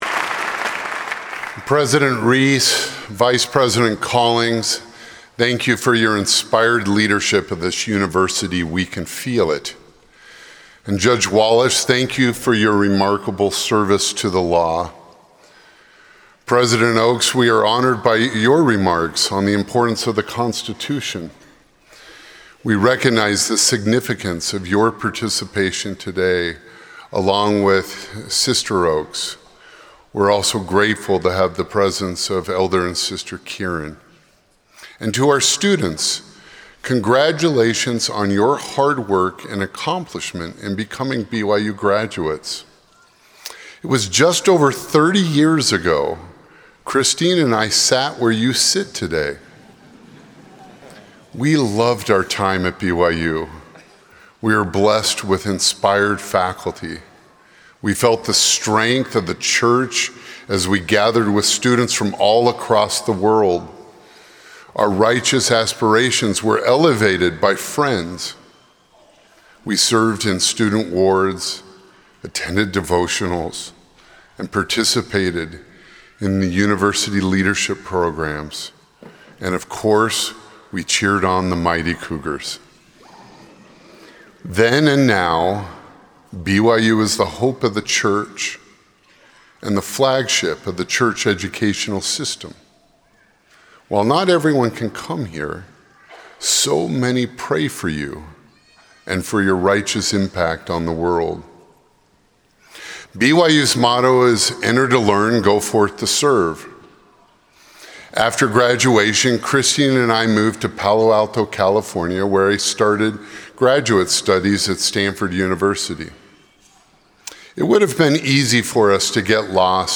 Audio recording of A Light to the World: The Paradox of the BYU Graduate Student by Clark G. Gilbert
BYUS-Commencement-2025_Clark-G.-Gilbert.mp3